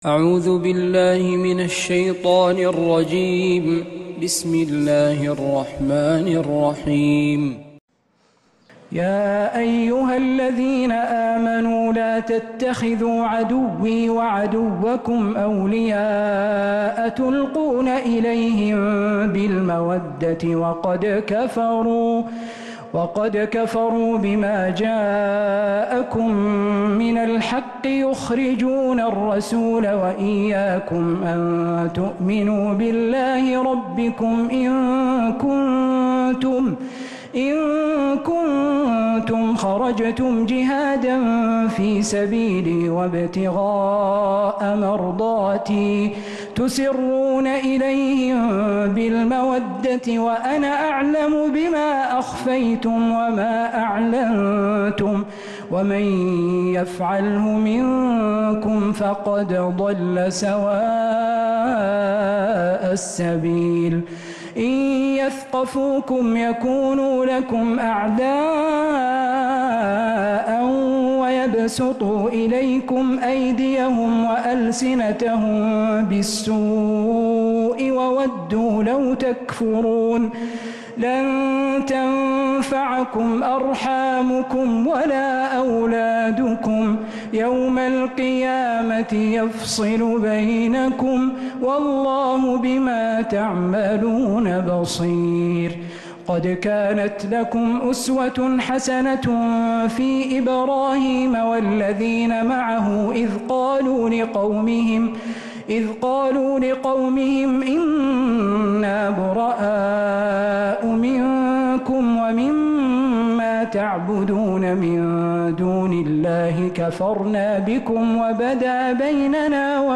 سورة الممتحنة من تراويح الحرم النبوي